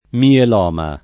[ miel 0 ma ]